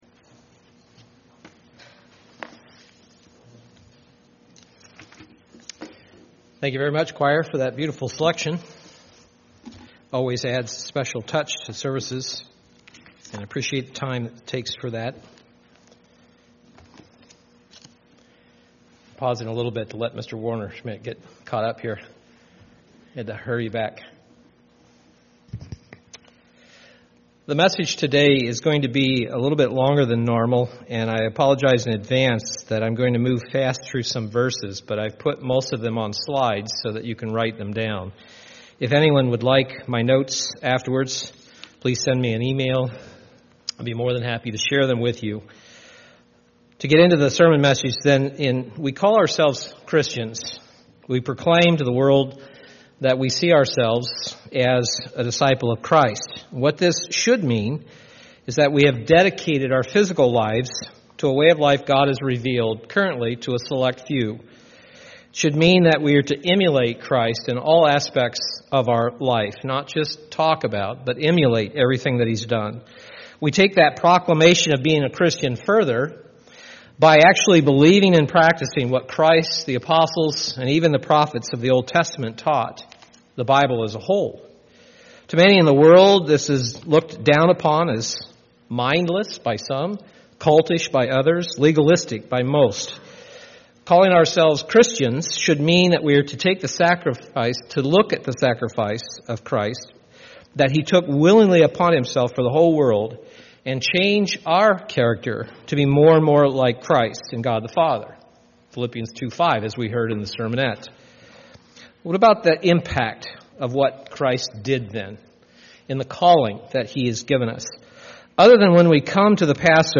UCG Sermon Notes The Sacrifice of Christ (or: Christ’s Crucifixion) In calling ourselves Christian, we proclaim to the world that we consider ourselves a disciple of Christ.